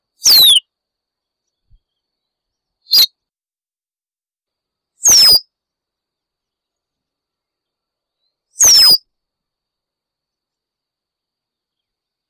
Sporophila ruficollis - Capuchino garganta negra
capuchinogargantanegra.wav